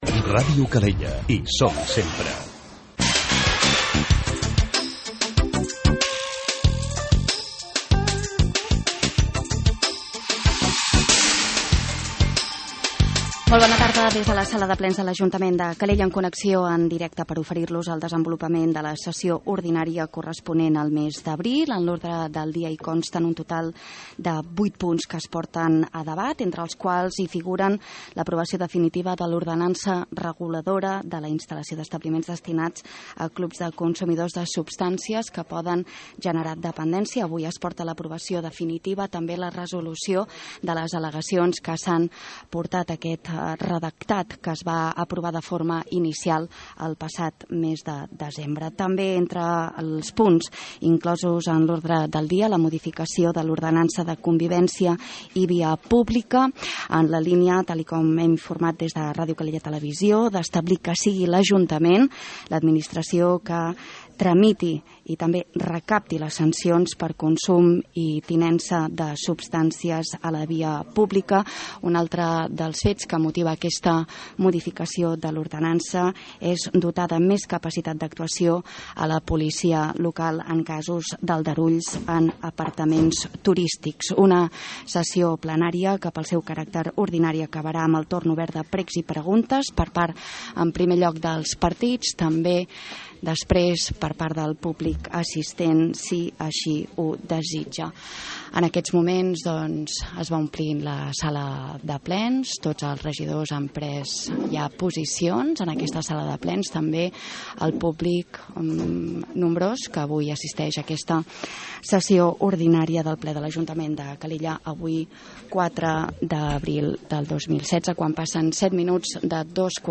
Sessió ordinària del ple de l'Ajuntament de Calella